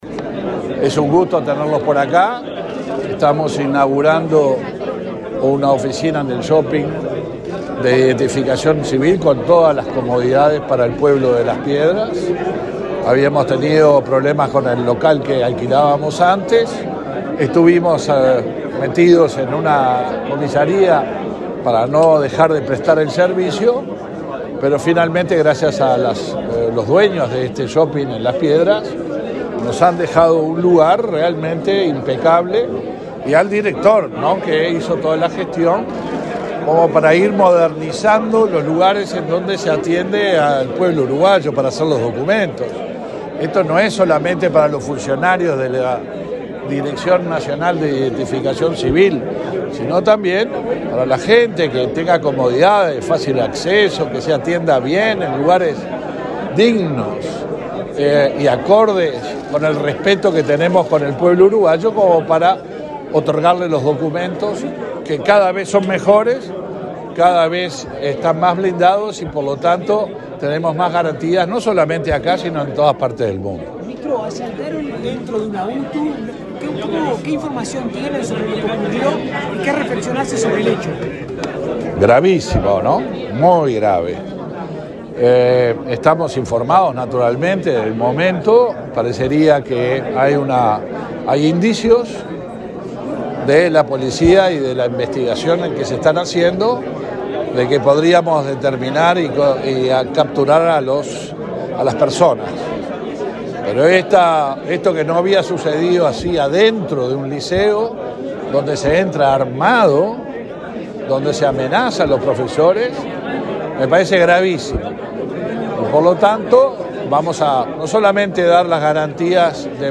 Declaraciones del ministro del Interior, Luis Alberto Heber
Declaraciones del ministro del Interior, Luis Alberto Heber 23/08/2023 Compartir Facebook X Copiar enlace WhatsApp LinkedIn El director nacional de Identificación Civil, José Luis Rondán, y el ministro Luis Alberto Heber, inauguraron un nuevo local de la referida repartición en el shopping Las Piedras, en Canelones. Luego, el secretario de Estado fue entrevistado para medios periodísticos.